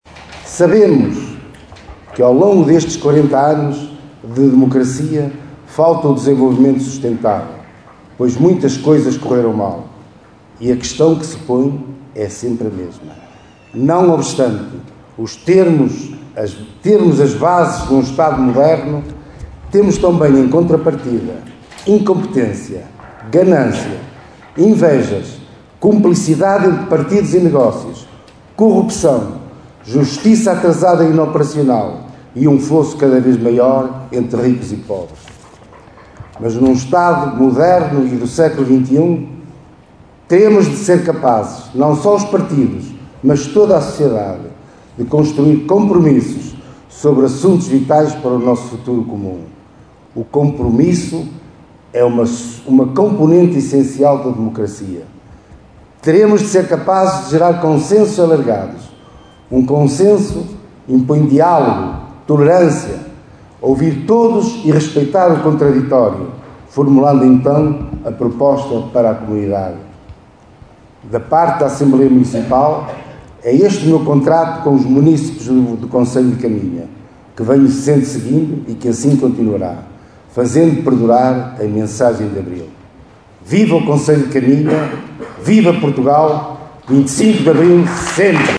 Foi com chuva que o 25 de Abril foi celebrado esta manhã em Caminha.